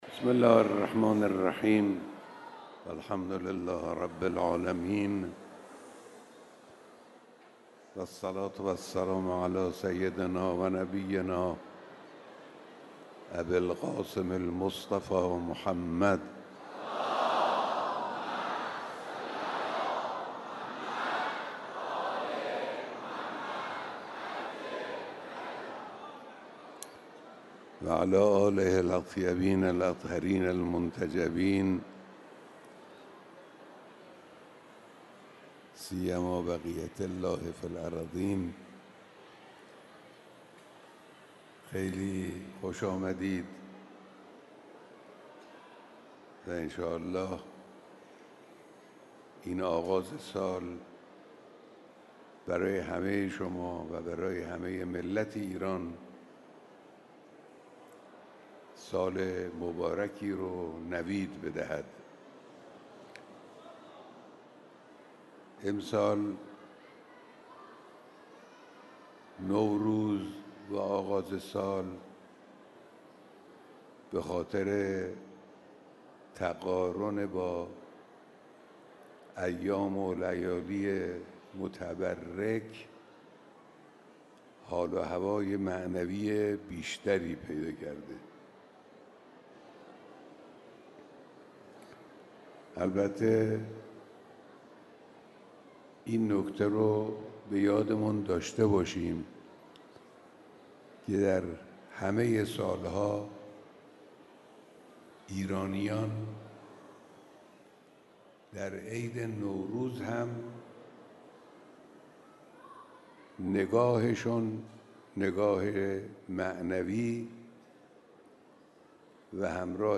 بیانات در دیدار نوروزی با هزاران نفر از قشرهای مختلف مردم